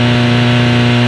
Sfx / Engines
1 channel